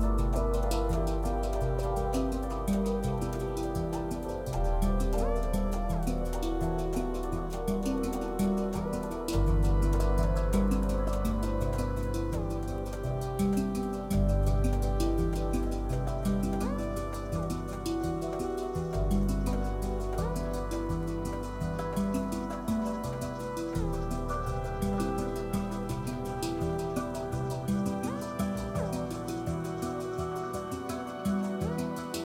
overlapping-music.wav